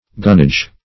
Gunnage \Gun"nage\, n. The number of guns carried by a ship of war.